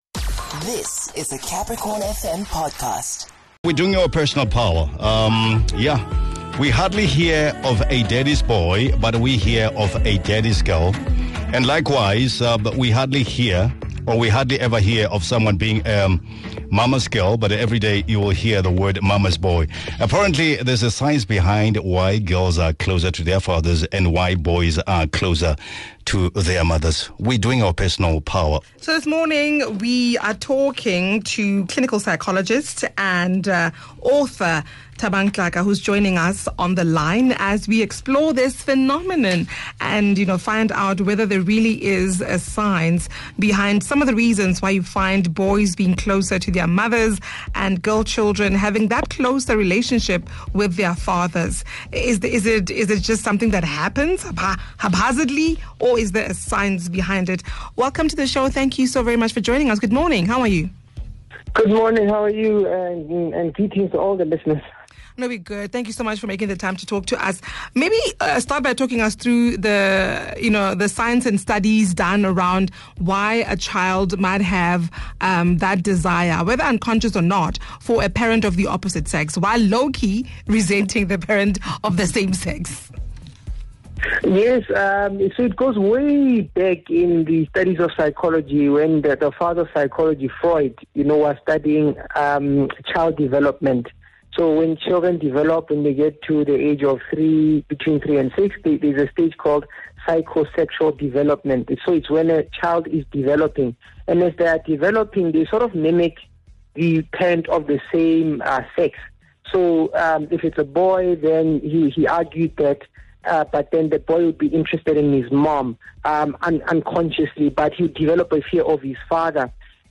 talk to Clinical Psychologist